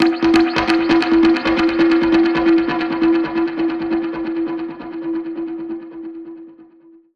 Index of /musicradar/dub-percussion-samples/134bpm
DPFX_PercHit_E_134-10.wav